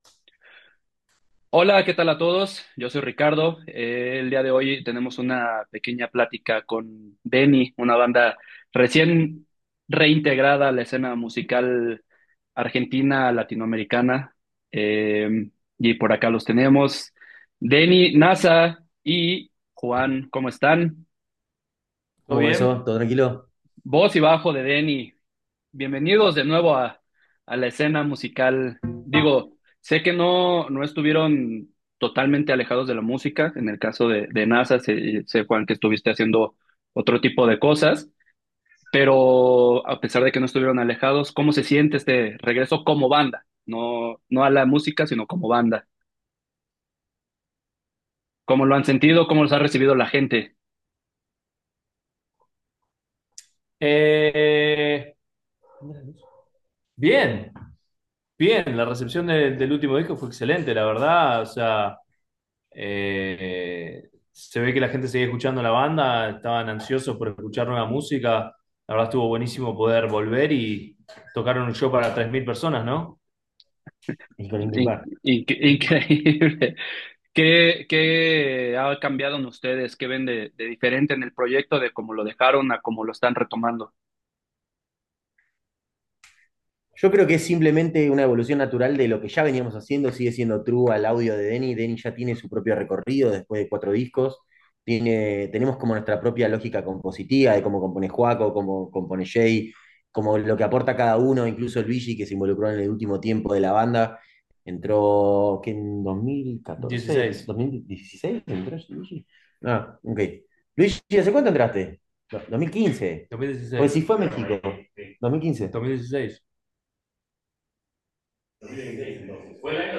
Entrevista: Deny "Documento 4"